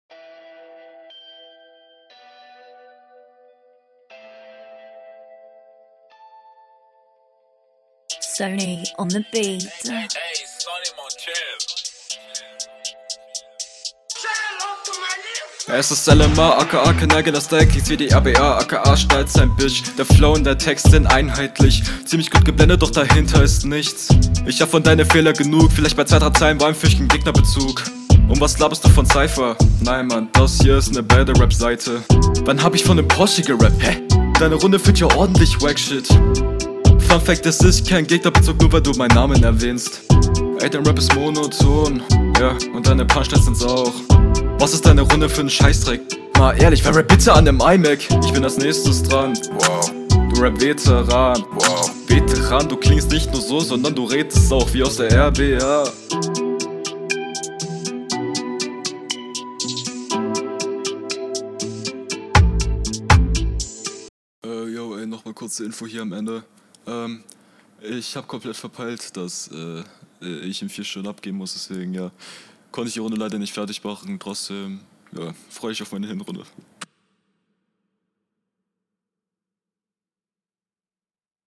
Boi die Stimme ist viel zu laut.